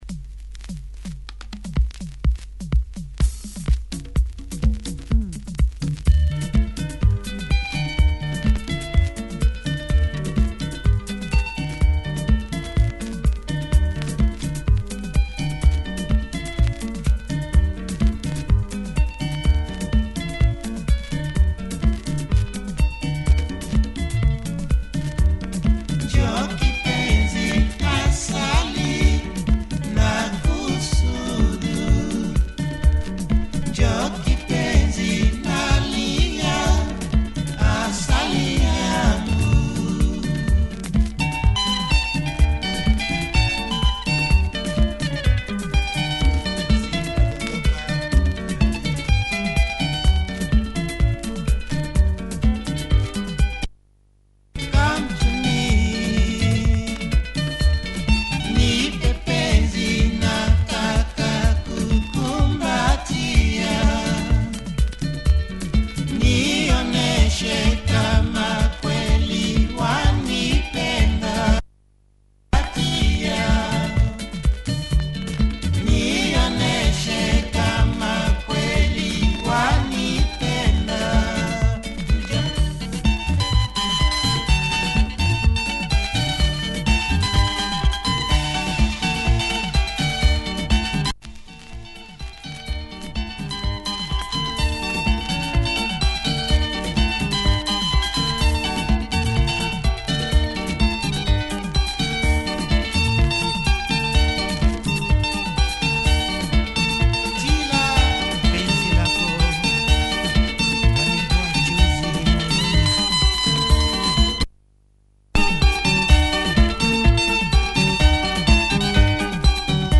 Modern soukouss sung in Swahili, check audio of both sides.
Loud pressing!